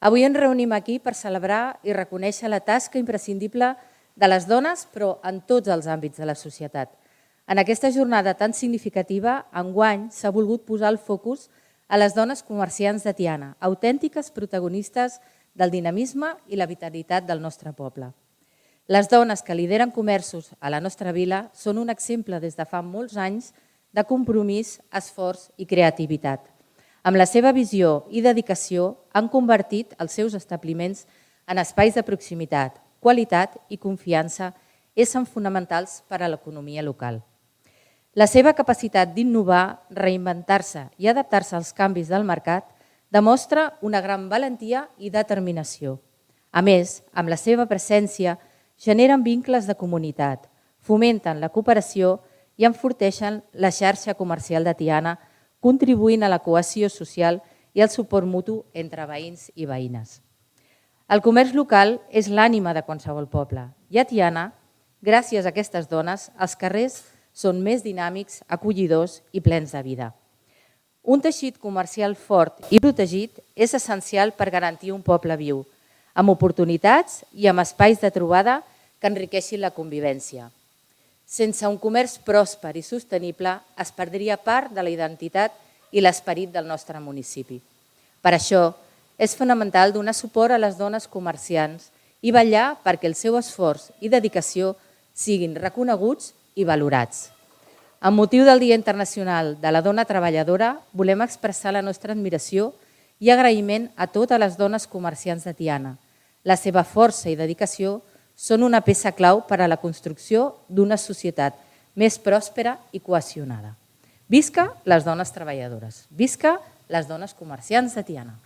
L’acte institucional del 8M, que s’ha celebrat aquest divendres 7 de març a Can Riera, ha donat el tret de sortida a les activitats programades a Tiana per aquest mes de març amb motiu del Dia de la Dona.
manifest-8M-online-audio-converter.com_.mp3